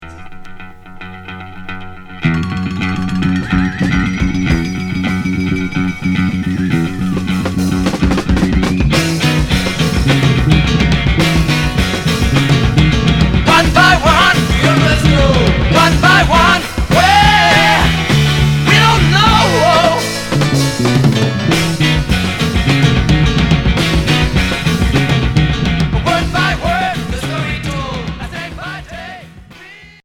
Heavy rock progressif Premier 45t retour à l'accueil